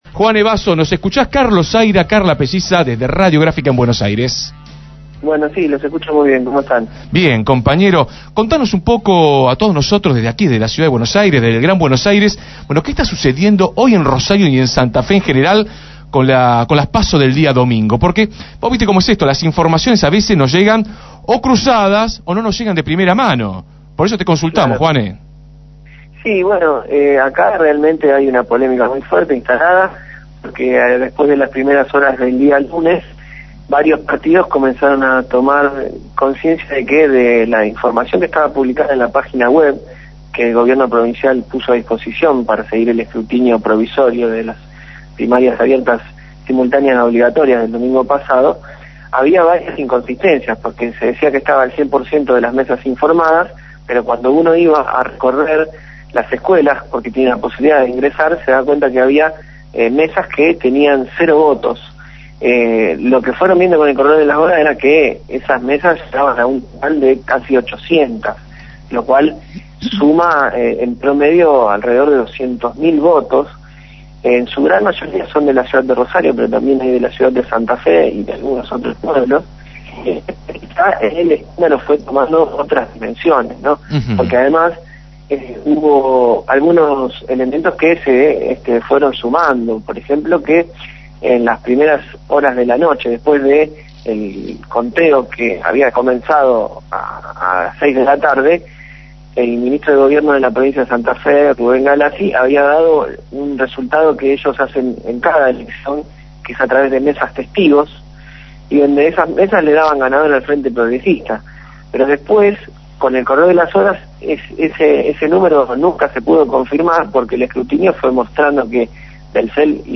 detalló la situación en comunicación con Desde el Barrio.